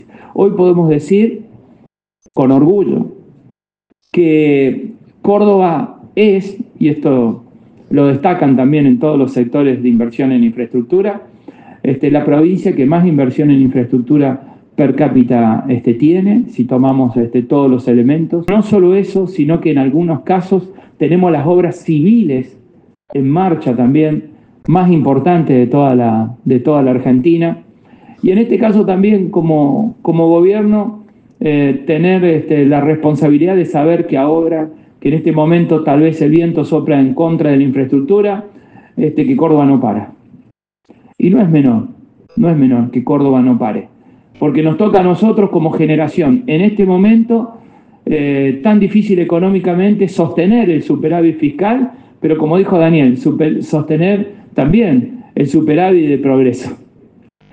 Audio: gobernador Martín Llaryora